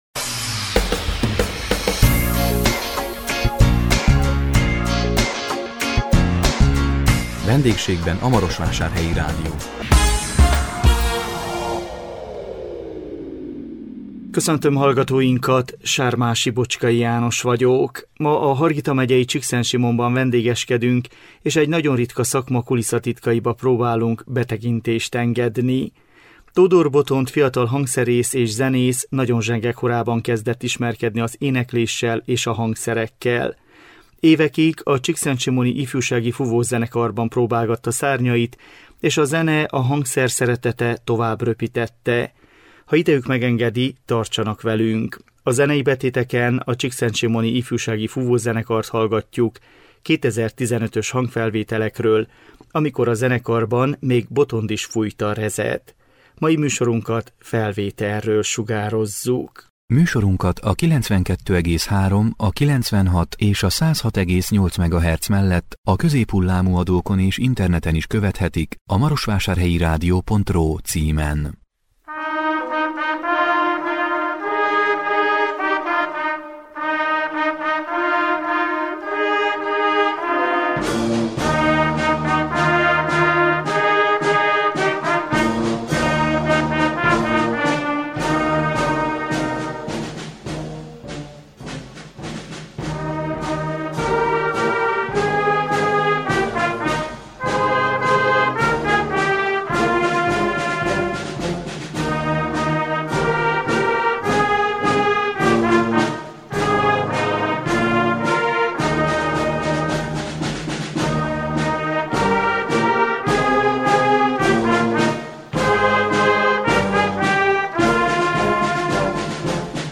A 2023 május 25-én jelentkező VENDÉGSÉGBEN A MAROSVÁSÁRHELYI RÁDIÓ című műsorunkkal a Hargita megyei Csíkszentsimonban vendégeskedtünk és egy nagyon ritka szakma kulisszatitkaiba próbáltunk betekintést engedni.